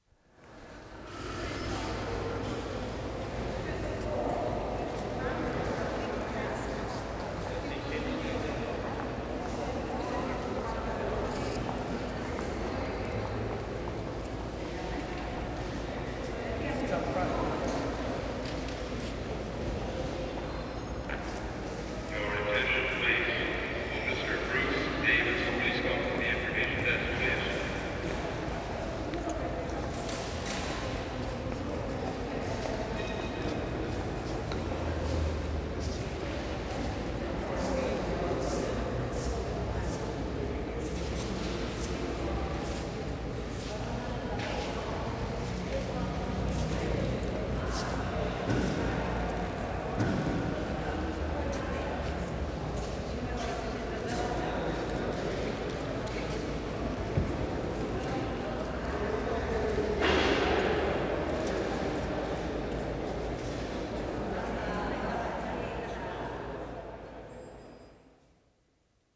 WORLD SOUNDSCAPE PROJECT TAPE LIBRARY
*5. Staged p.a. announcement. Recordist's colleague has a fake message for recordist, to hear the p.a. system in this highly reverberant space.